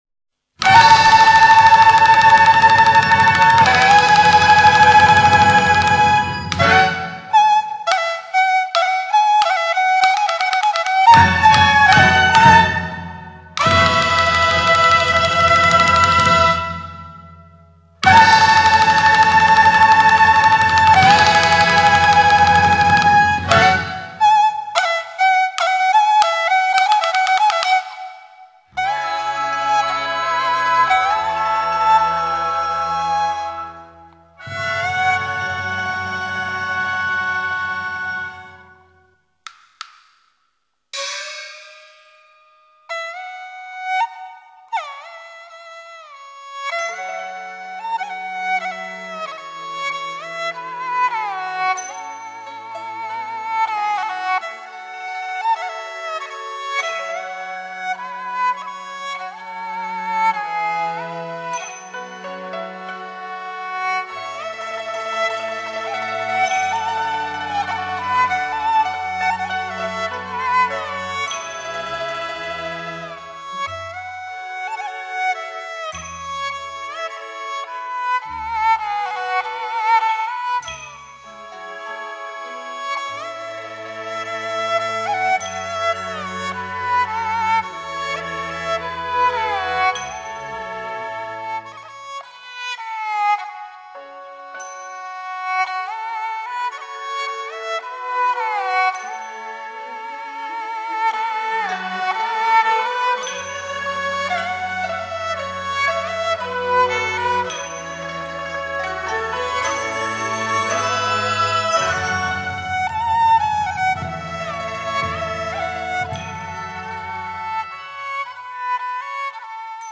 饱蘸浓郁厚重的东方民族情愫，娓娓地诉说；
满怀热烈奔放的世界风情，自由地表达。中国民族音乐与世界流行音乐在这里真挚交流，